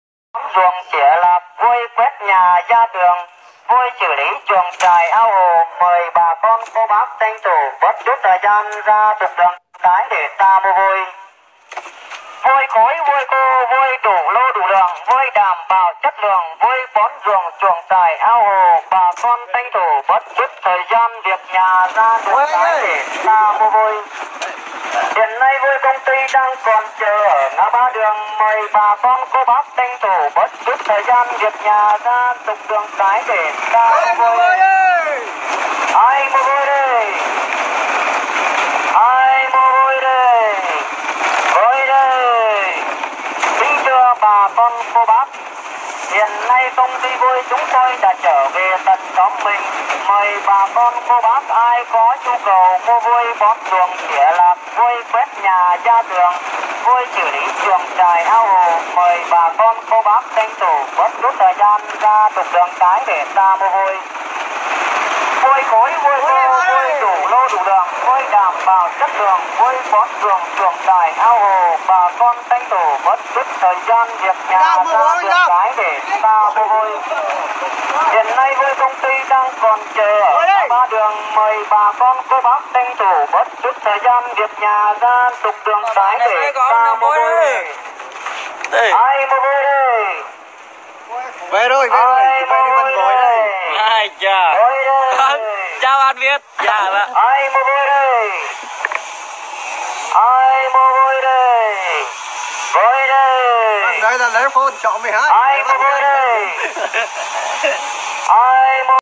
Tiếng Rao Bán Vôi (MP3)